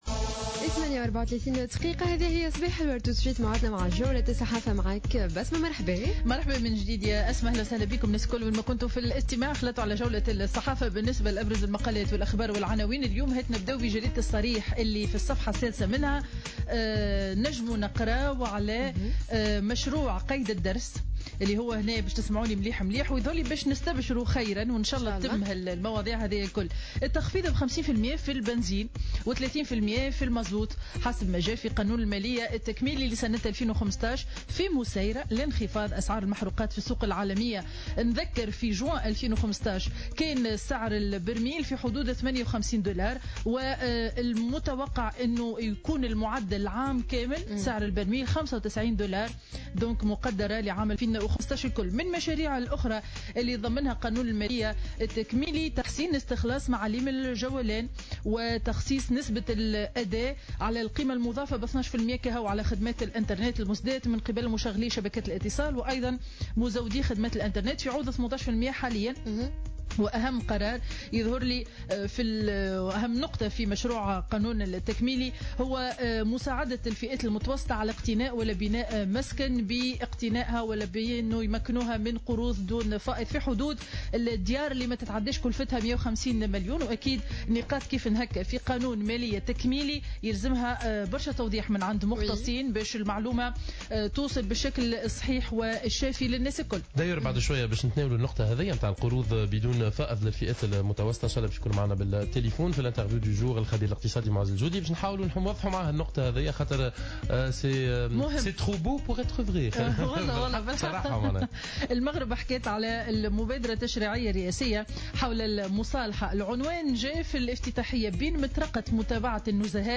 Revue de presse du mardi 21 juillet 2015